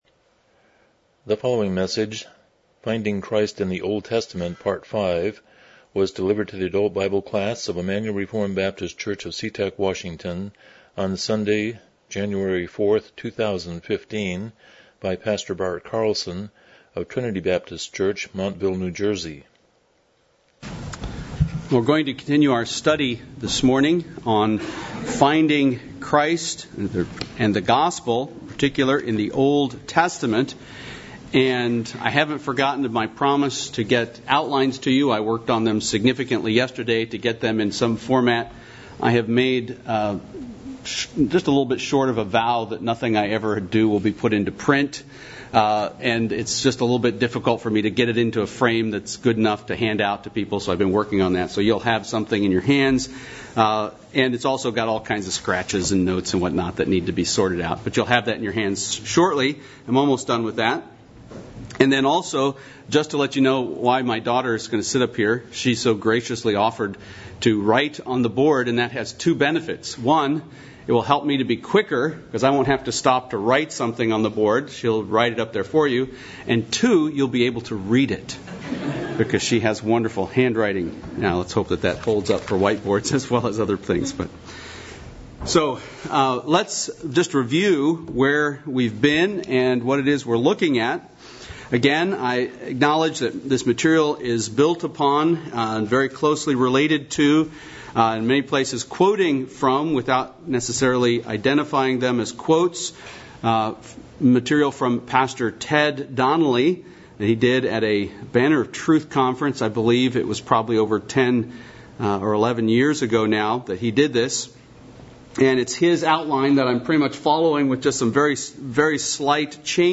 Miscellaneous Service Type: Sunday School « Finding Christ in the Old Testament